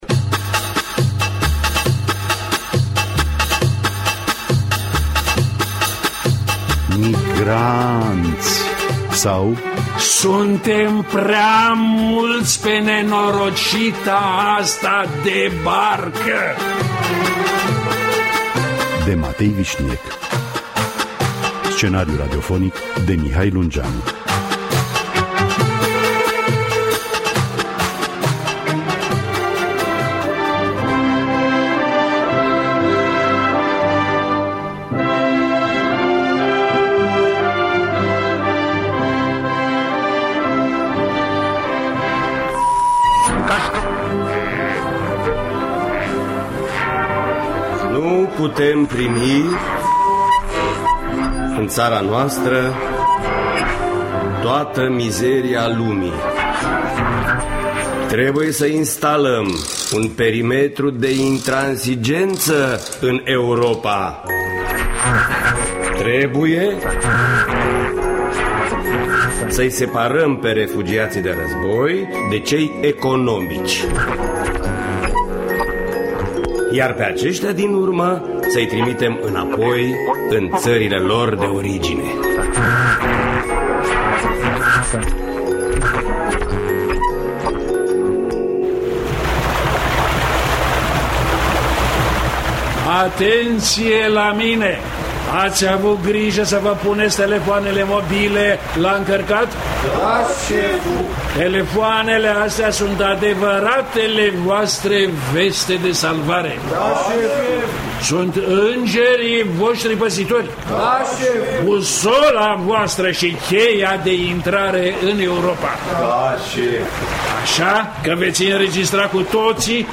Migraaaanți sau Suntem prea mulți pe nenorocita asta de barcă de Matei Vișniec – Teatru Radiofonic Online
Scenariul radiofonic